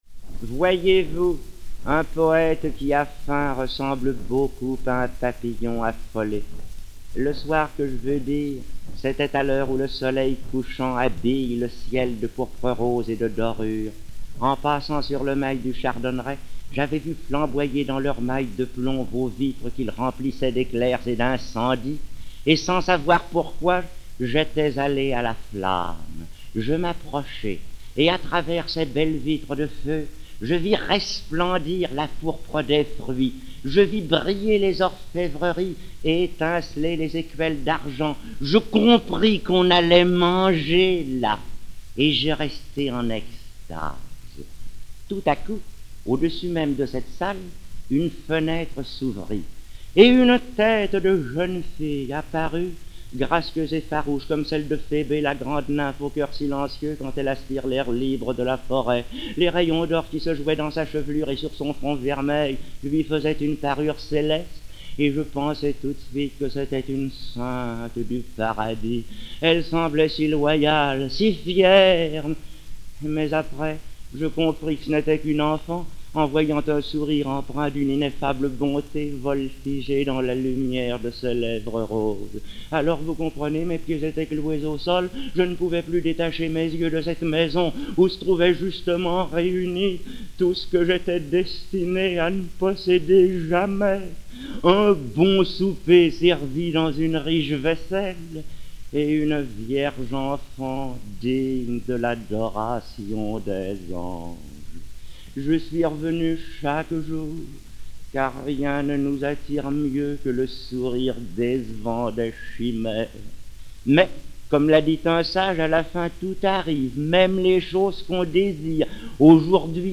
Georges Berr, sociétaire de la Comédie-Française